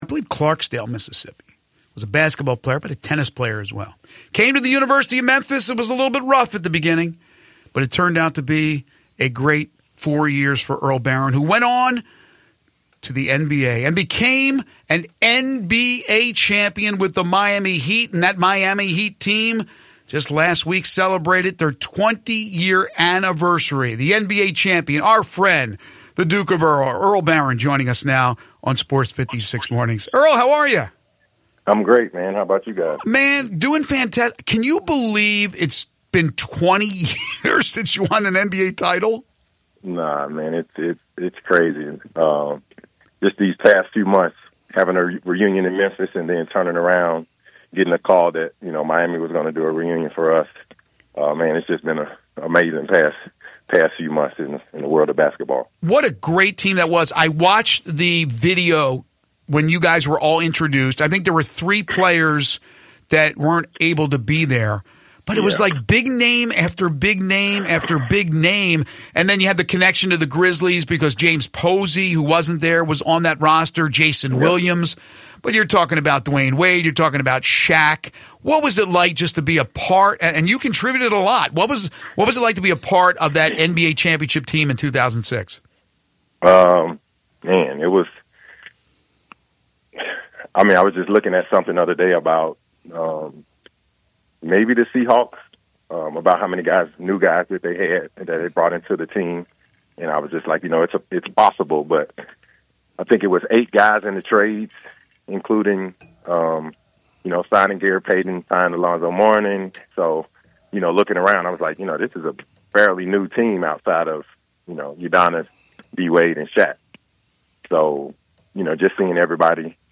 Fomer Memphis Tigers Basketball player & NBA Champion Earl Barron joins Sports56 Mornings to talk Memphis Tigers, Heat NBA Championship, and more